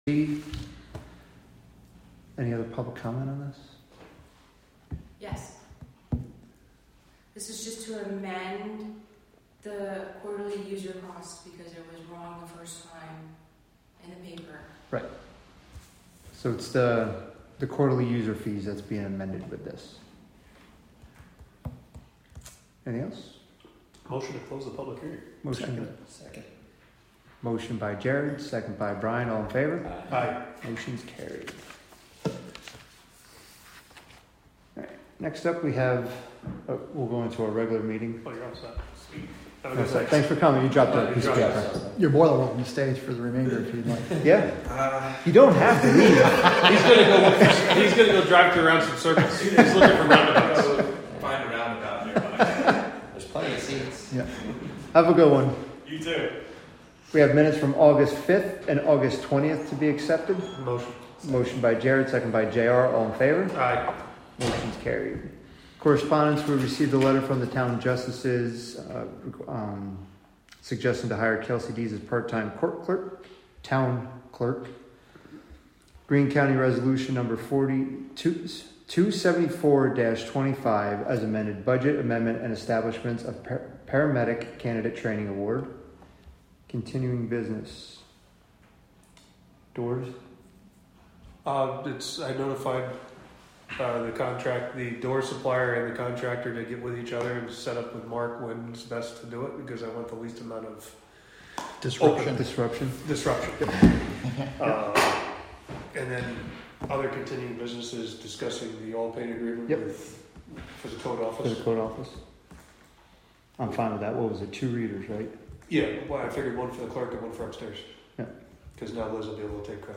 Live from the Town of Catskill: September 2, 2025 Catskill Town Board Committee Meeting (Audio)